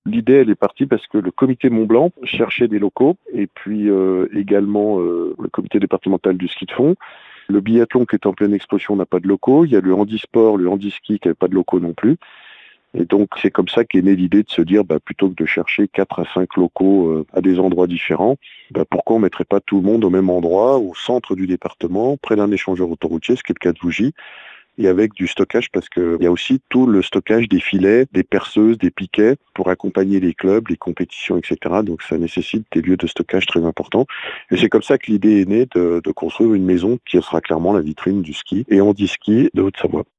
Martial Saddier, son président, explique d'où est partie cette idée.